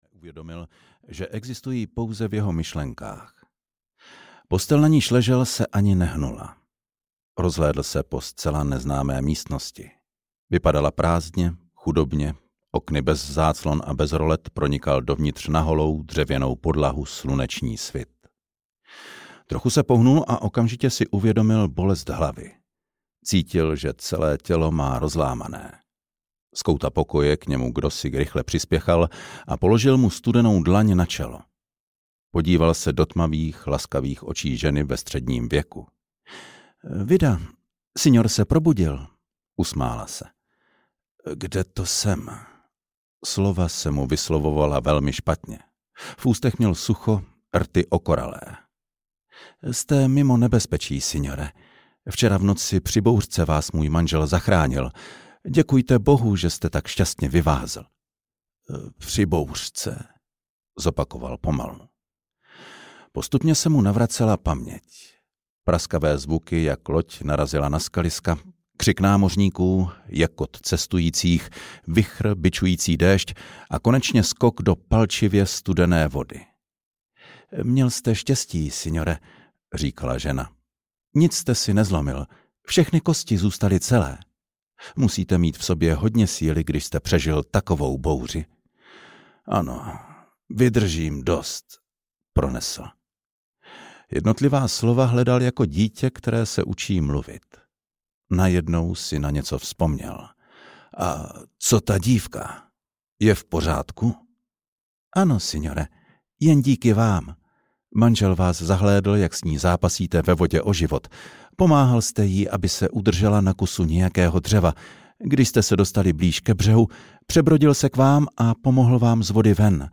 Zlatá gondola audiokniha
Ukázka z knihy
zlata-gondola-audiokniha